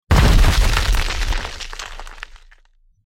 sfx updates